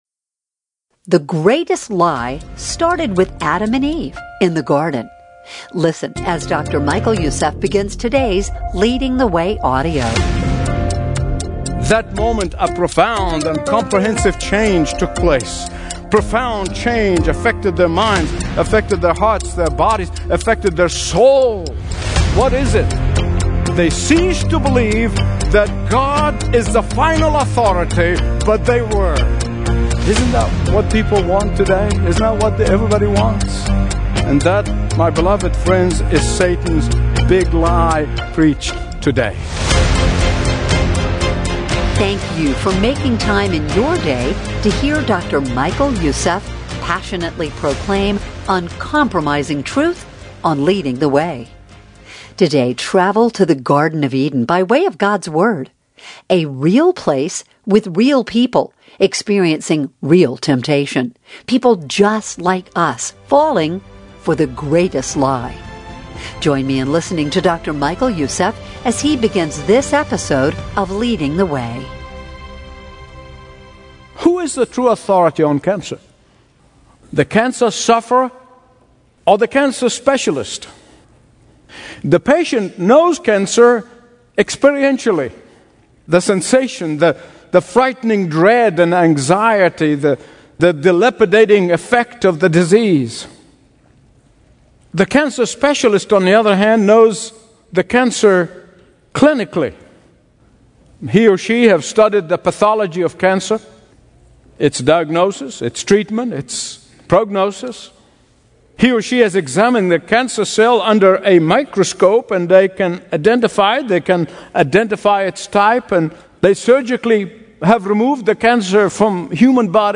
Stream Expository Bible Teaching & Understand the Bible Like Never Before